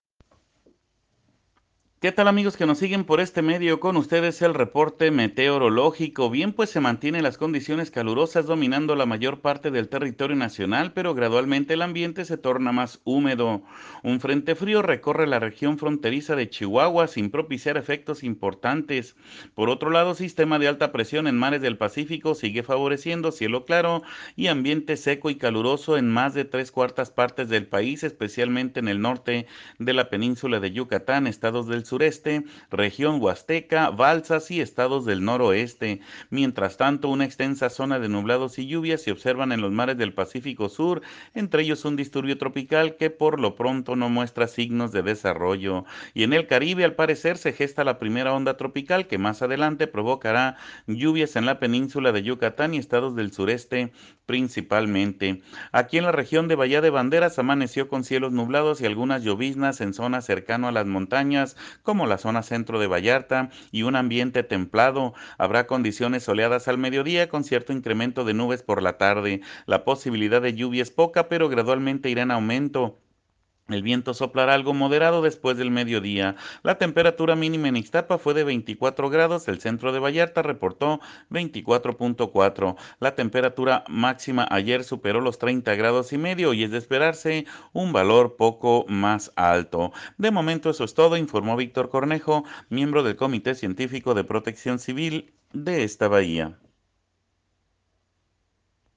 AUDIO: escuche al meteorólogo